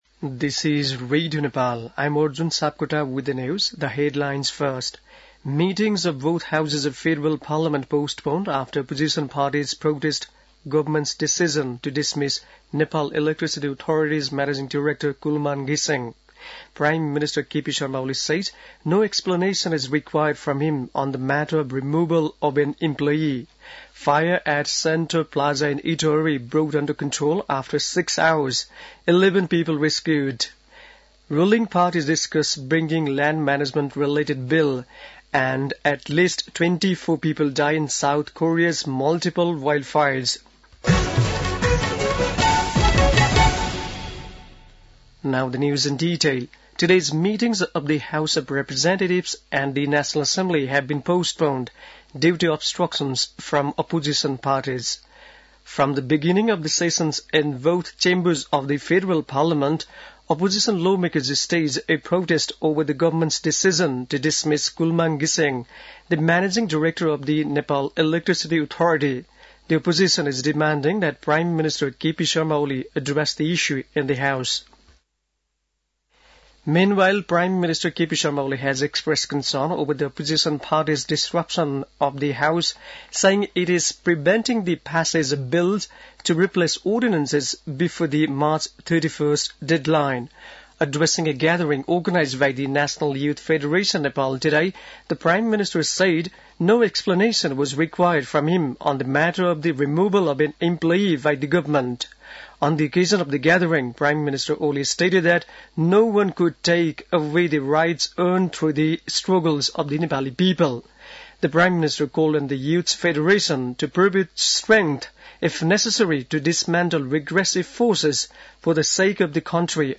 An online outlet of Nepal's national radio broadcaster
बेलुकी ८ बजेको अङ्ग्रेजी समाचार : १३ चैत , २०८१